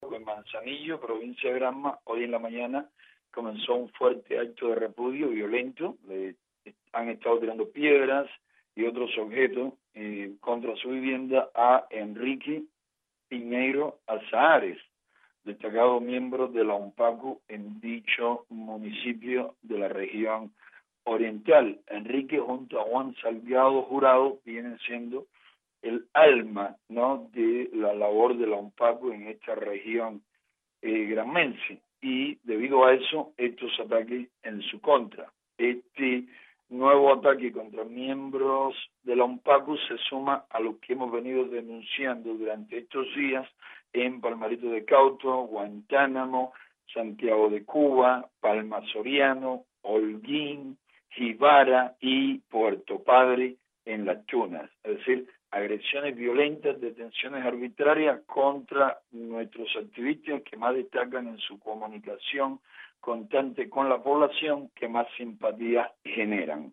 En la región oriental de Cuba continuan las agresiones contra opositores y miembros de la Unión Patriótica de Cuba, en esta ocación , ocurre en Manzanillo, provincia Granma. José Daniel Ferrer, coordinador de la UNPACU, ofrece los detalles.